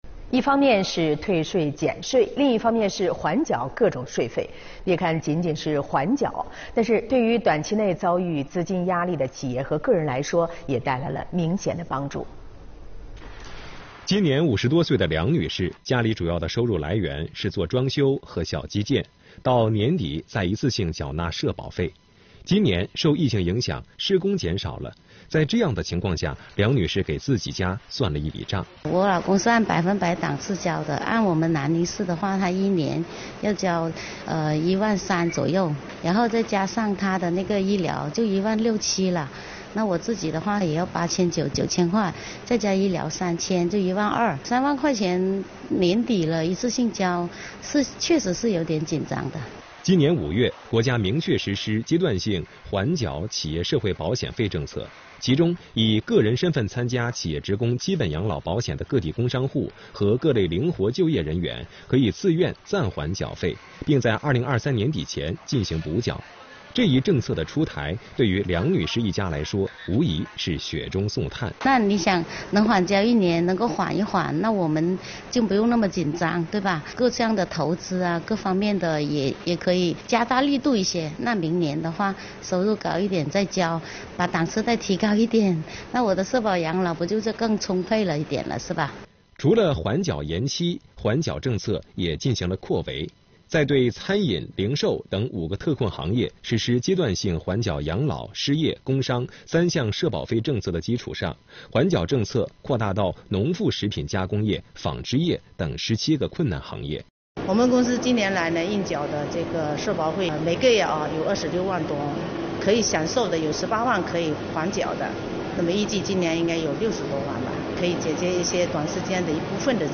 南宁市税务部门聚焦市场主体“急难愁盼”，积极落实阶段性缓缴社保费扩围延期等政策。9月15日，央视新闻频道直播间《阶段性缓缴社保费扩围延期 缓解资金压力》作出报道。